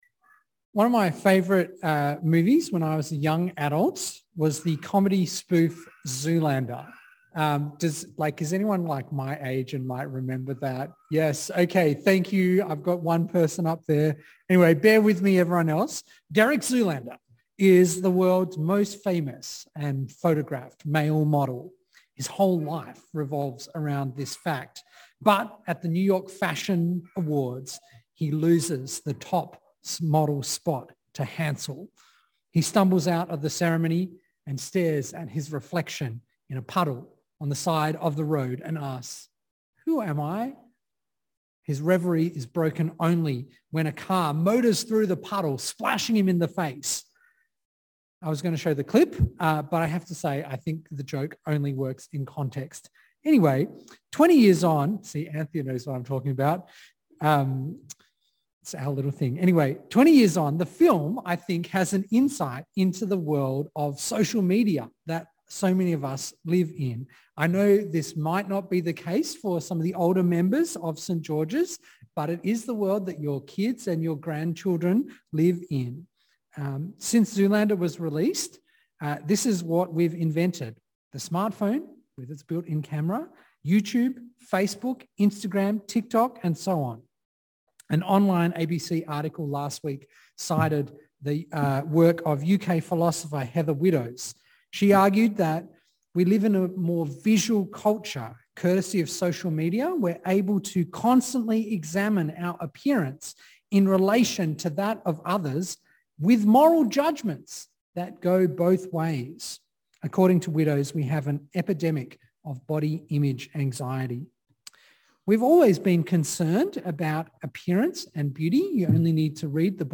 This sermon explores who God says we are and spiritual disciplines that help us relinquish the false self and live the life God calls us too.